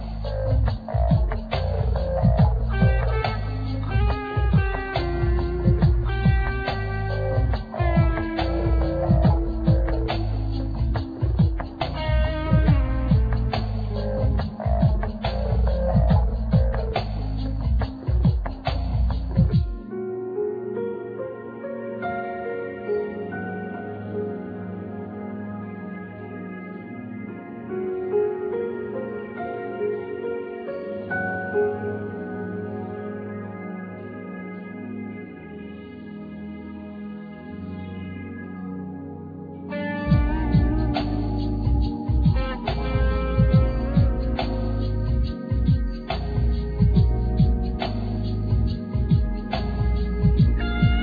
Acoutic & electric piano,Vocals
Keyboards,Electronics,Loops,Electric piano
Pedal steel guitar
Trumpet
Treated Kantele
Double bass